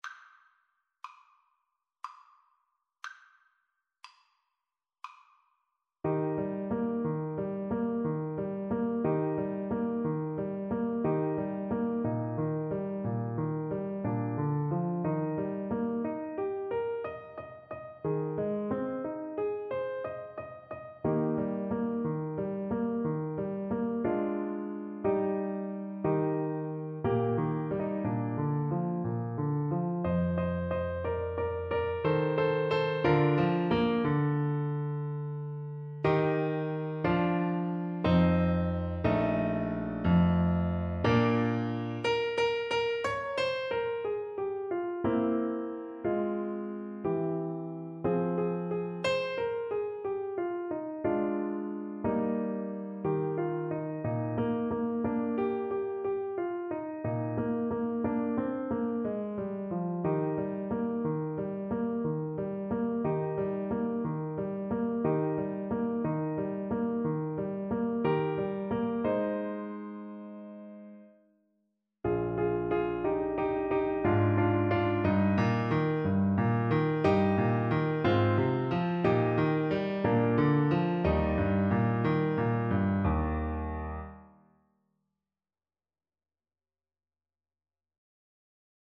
Clarinet
Eb major (Sounding Pitch) F major (Clarinet in Bb) (View more Eb major Music for Clarinet )
Bb4-G6
Andante =60
3/4 (View more 3/4 Music)
Classical (View more Classical Clarinet Music)